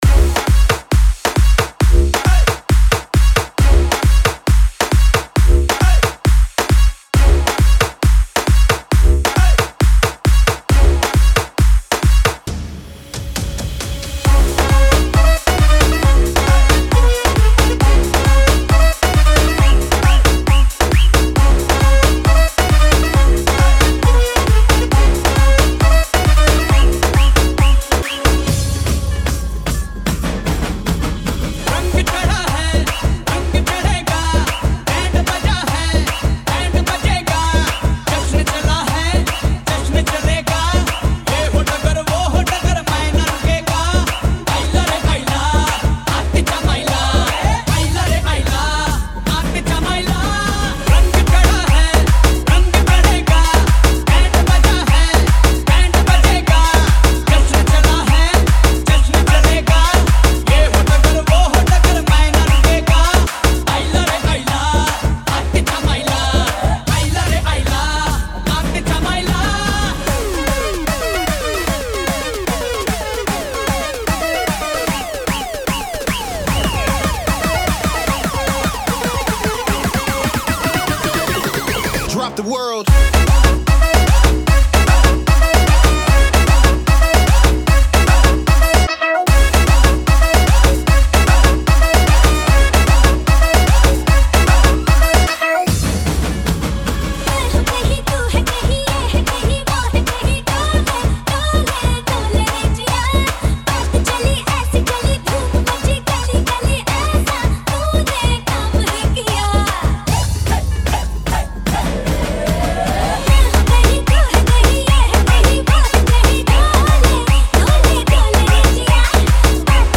Circuit Mix